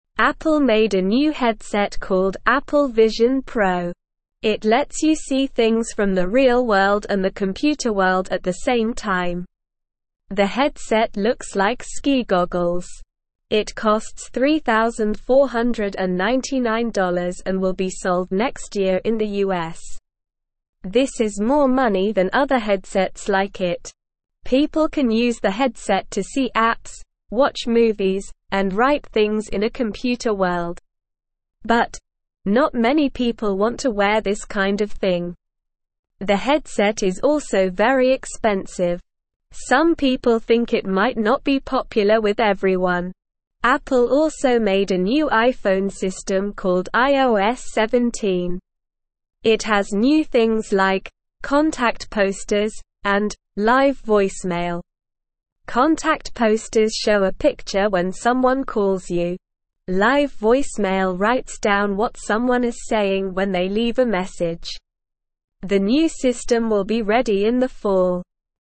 Slow
English-Newsroom-Beginner-SLOW-Reading-New-Apple-Headset-Mixes-Real-and-Computer-Worlds.mp3